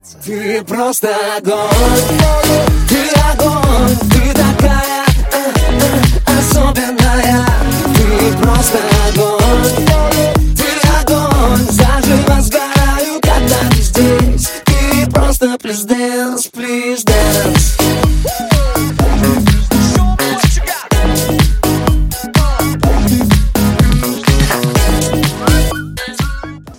поп
мужской вокал
заводные
dance
озорные